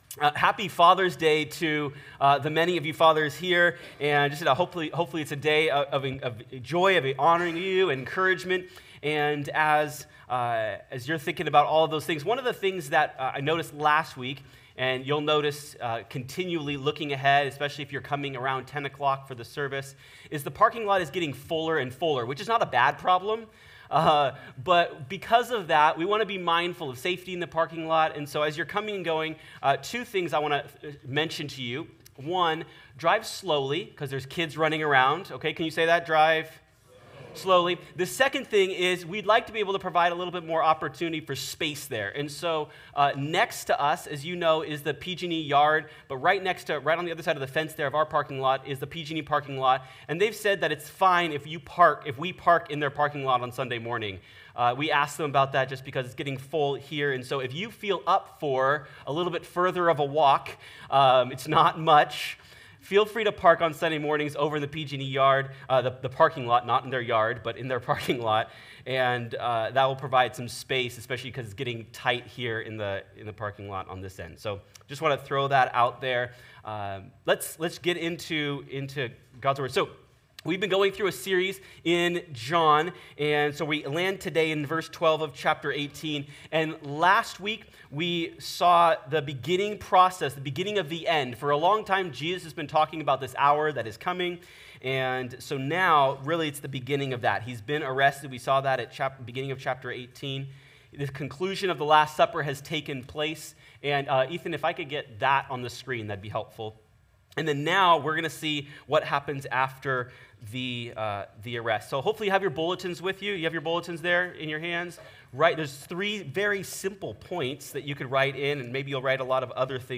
After Jesus' arrest in the garden, He is passed around, all night, between those who question, beat, and mock Him. In our text today, we find Jesus at the beginning of this unjust journey, denied by a close follower, and treacherously treated by religious leaders. Sermon Notes:Coming soon.